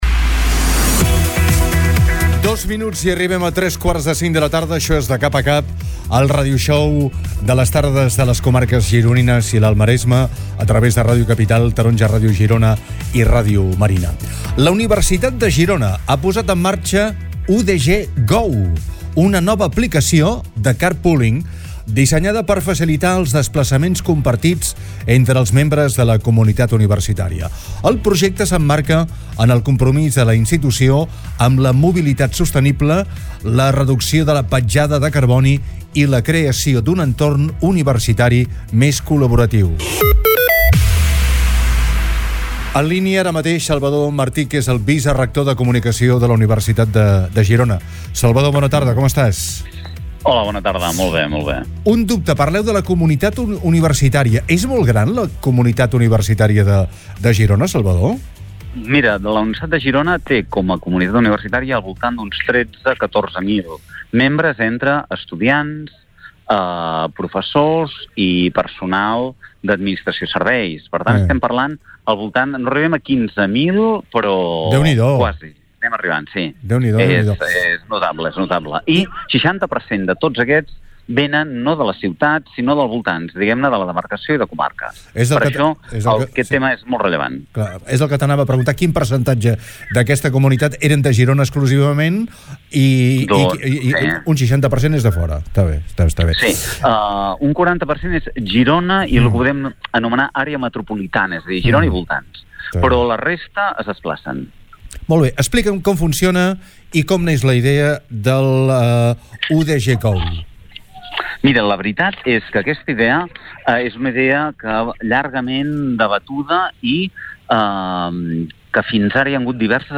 entrevistat